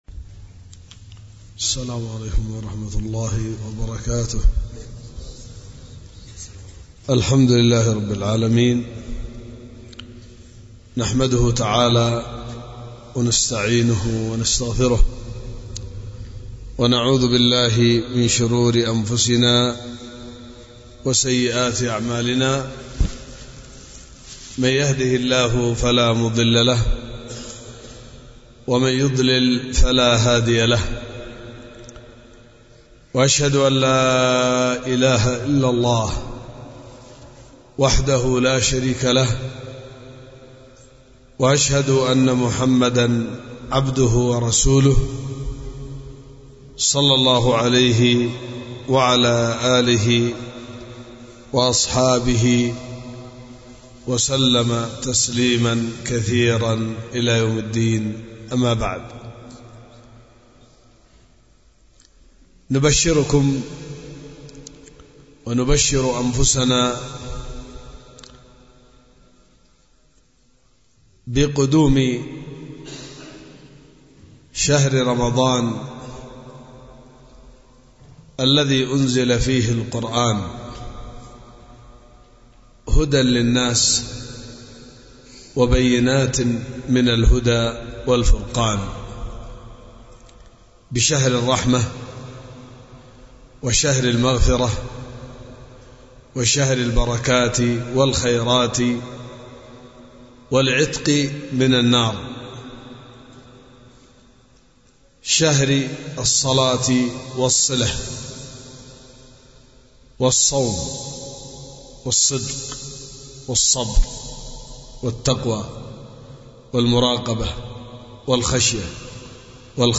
ألقيت في دار الحديث بوادي بنا – السدة – إب